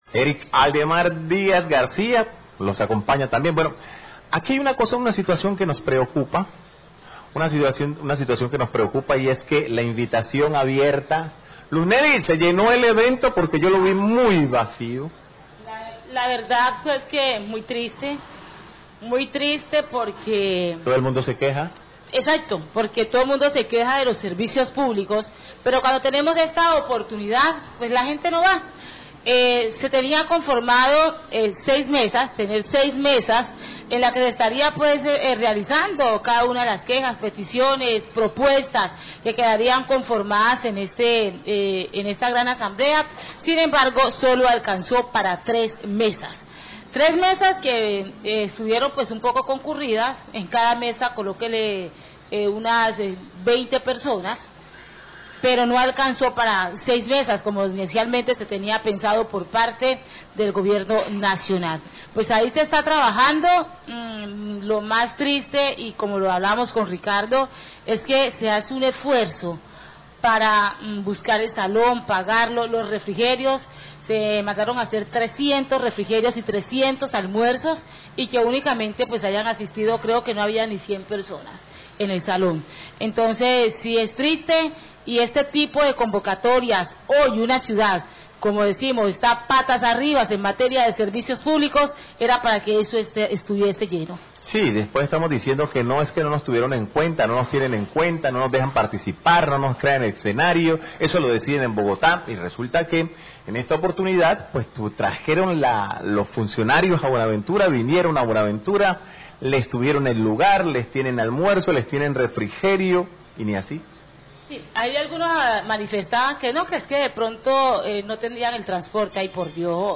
Radio
Periodistas critican la poca asistencia de la comunidad a la asamblea pública en relación con la modificación a la Ley 142 de servicios públicos; se esperaban crear 6 meses de discusión pero sólo alcanzaron para 3, se contabilizaron menos de 100 personas. cuando eran esperadas 300 asistentes.